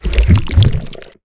digesting.wav